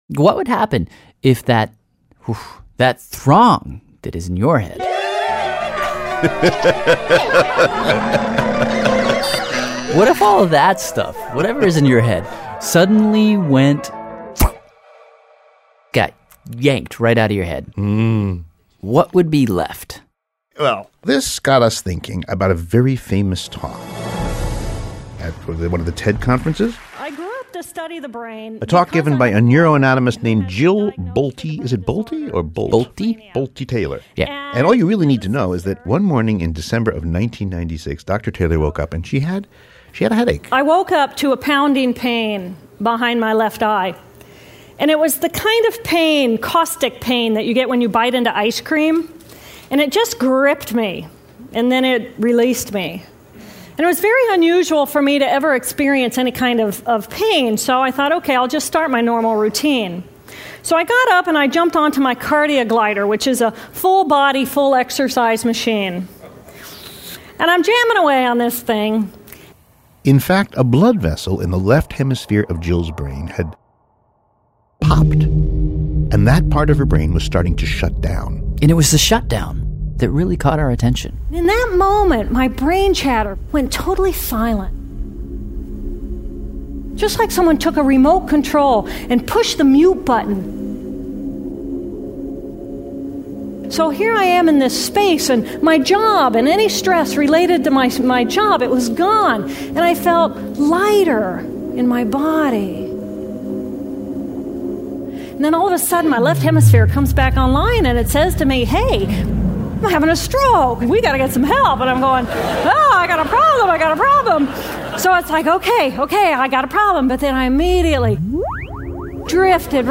This is an excerpt from the very excellent Radiolab podcast titled, Words. The relevant chapter tells the story of Jill Bolte Taylor, who is a neuroanatomist, who had a stroke and for a while, lost the language center of her brain. She describes in the podcast excerpt linked below her existence without language.